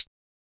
Closed Hats
TS HiHat_11.wav